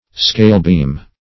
Search Result for " scalebeam" : The Collaborative International Dictionary of English v.0.48: Scalebeam \Scale"beam`\, n. 1. The lever or beam of a balance; the lever of a platform scale, to which the poise for weighing is applied.